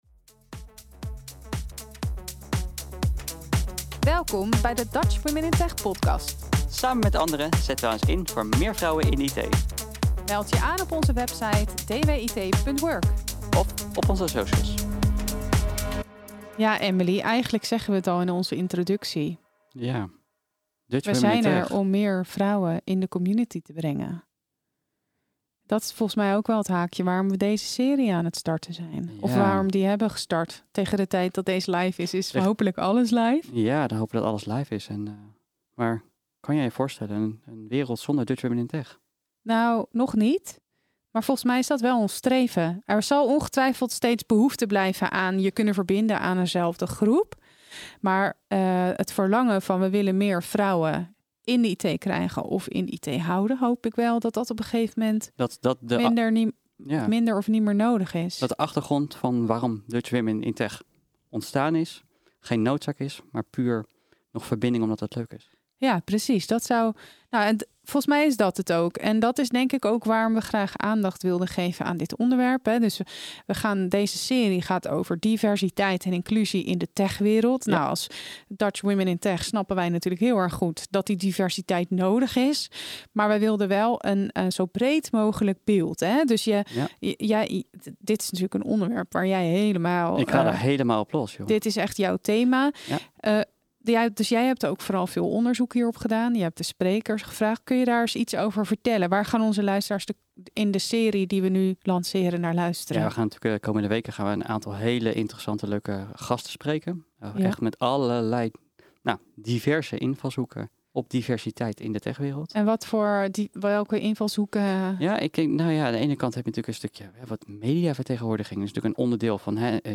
Jullie hosts van de aflevering: